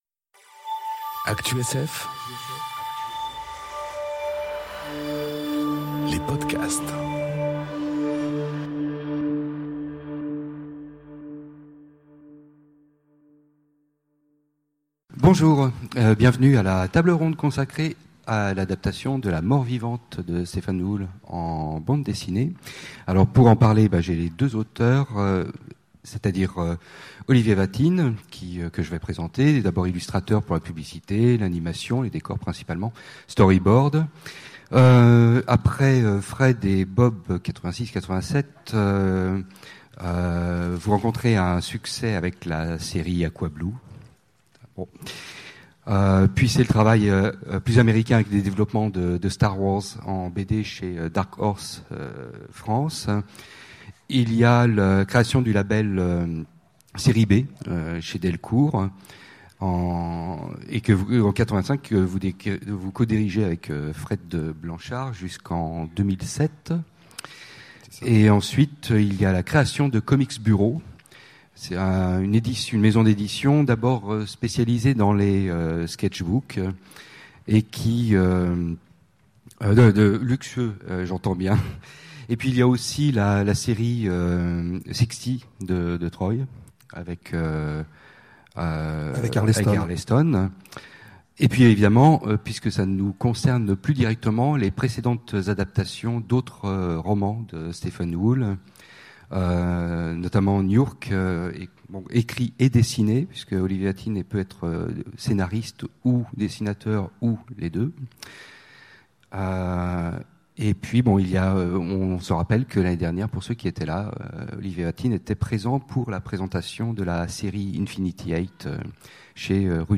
Conférence La Mort Vivante ou le Frankenstein moderne enregistrée aux Utopiales 2018